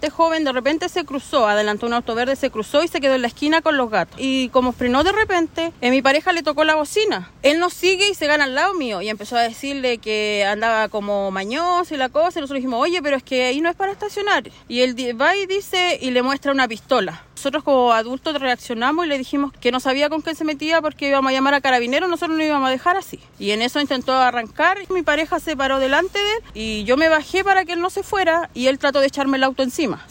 En conversación con Radio Bío Bío, una de las víctimas relató el momento en que sucedió el hecho, detallando que se encontraban en compañía de su hija pequeña cuando el sujeto los amenazó.
cuna-victimass.mp3